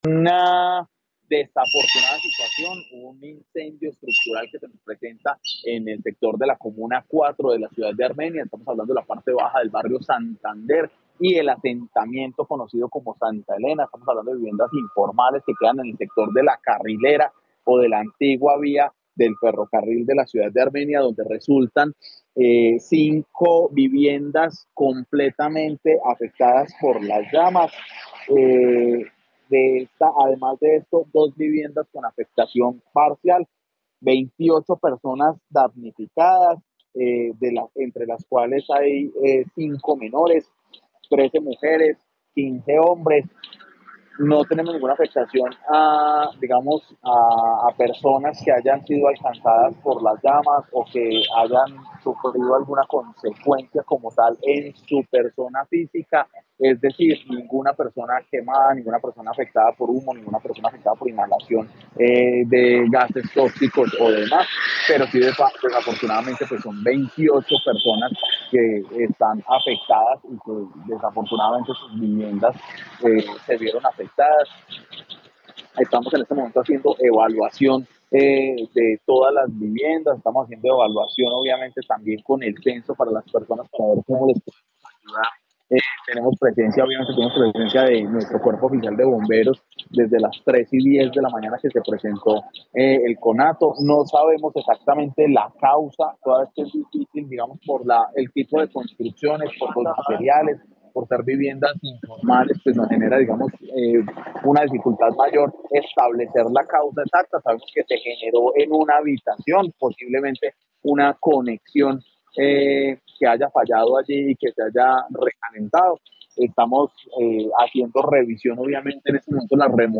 Andrés Buitrago secretario de gobierno de Armenia